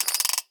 pull_chain_start.ogg